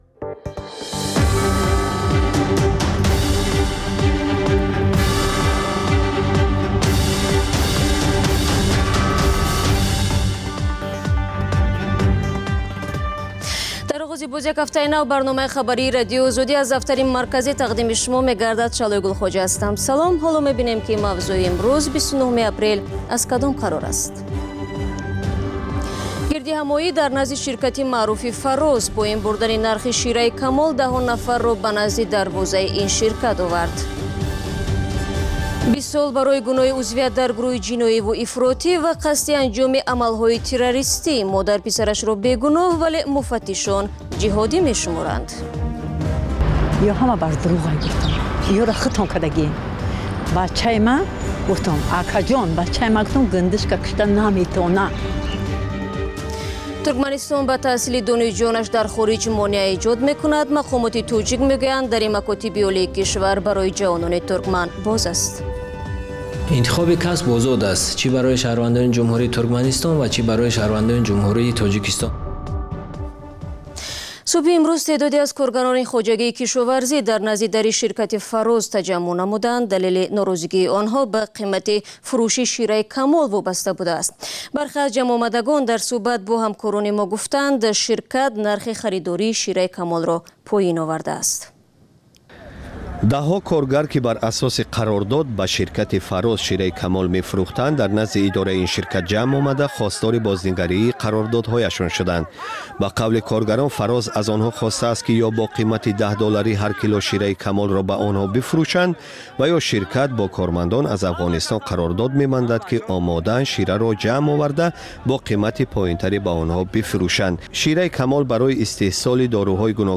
Маҷаллаи хабарӣ
Тозатарин ахбор ва гузоришҳои марбут ба Тоҷикистон, минтақа ва ҷаҳон дар маҷаллаи бомдодии Радиои Озодӣ